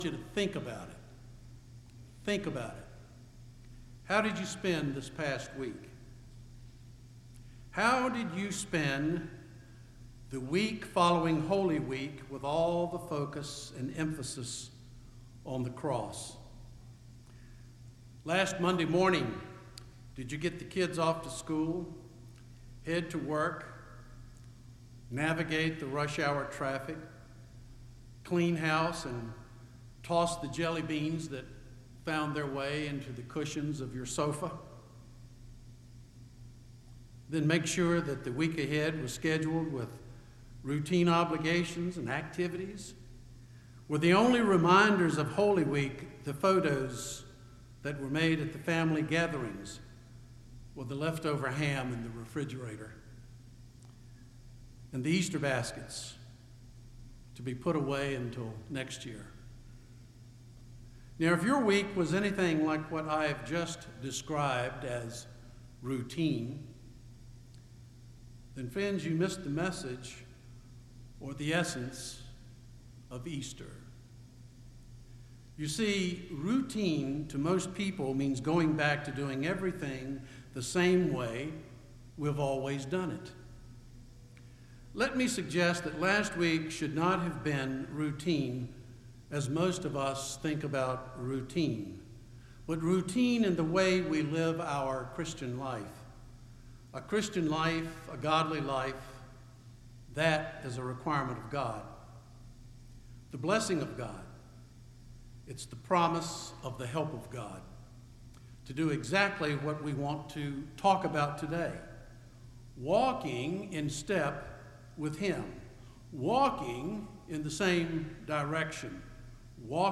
Sermon audio from the pulpit of First Evangelical Presbyterian Church Roanoke